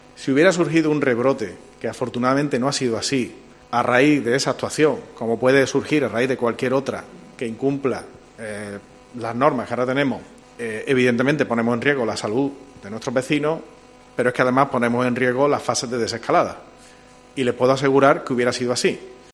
Escucha al alcalde, José Mª Bellido Roche